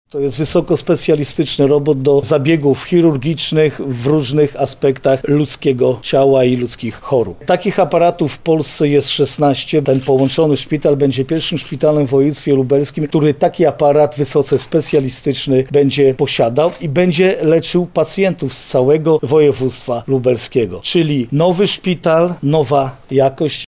• mówi Jarosław Stawiarski, marszałek województwa lubelskiego.